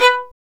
STR VLN JE1K.wav